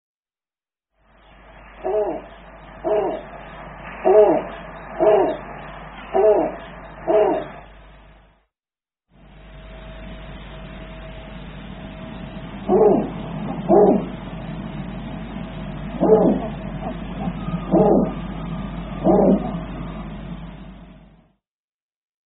Sonido de un búho blanco